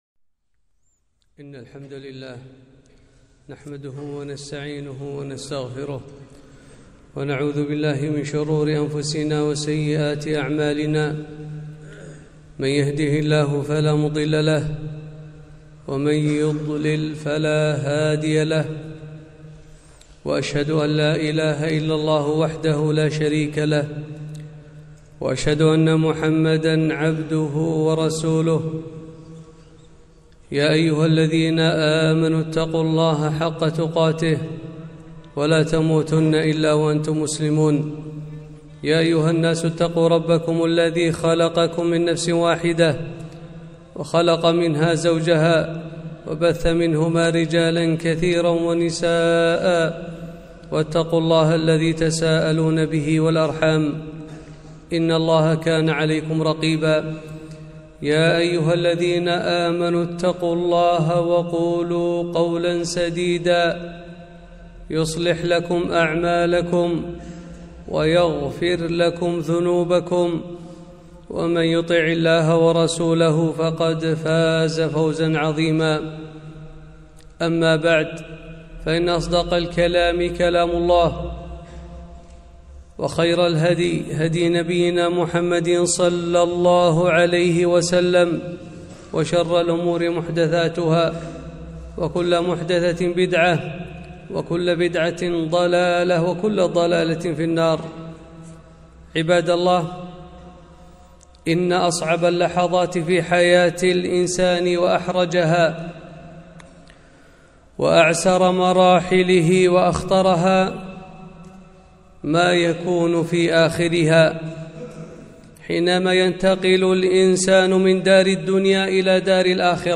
خطبة - أسباب حسن الخاتمة